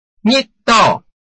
拼音查詢：【饒平腔】ngid ~請點選不同聲調拼音聽聽看!(例字漢字部分屬參考性質)